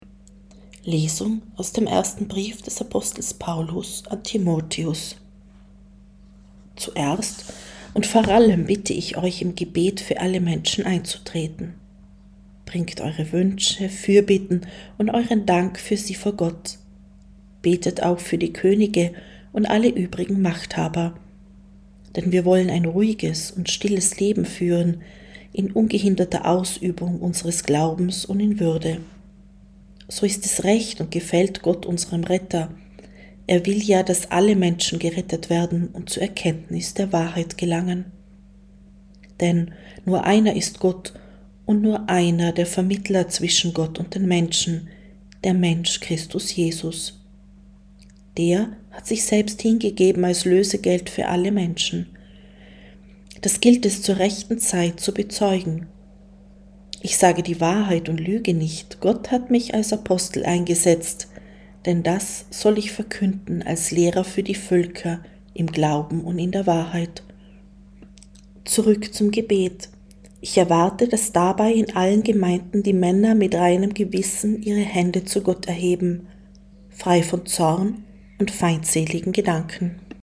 Wenn Sie den Text der 2. Lesung aus dem ersten Brief des Apostels Paulus an Timótheos anhören möchten: